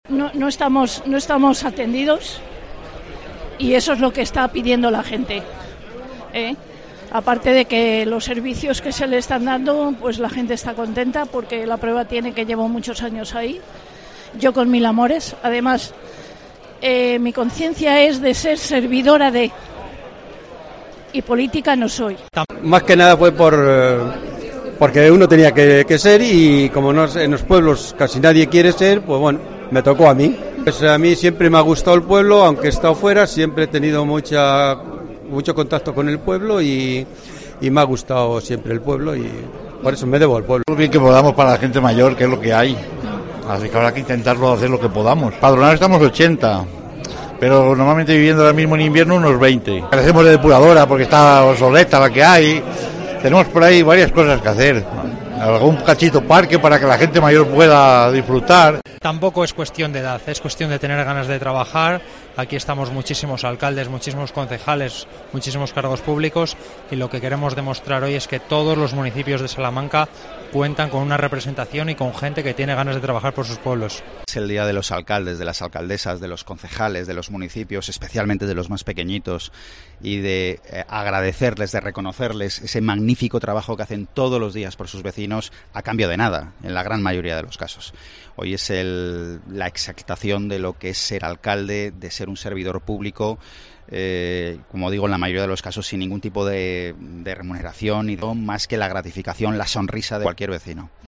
Testimonios de alcaldes de la provincia y del Presidente de la Diputación de Salamanca.